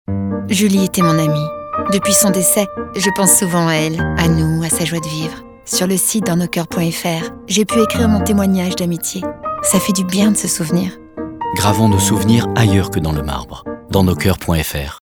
simple // concernée
Spot-radio-Dans-Nos-Coeurs-simpe-concernée-copie.mp3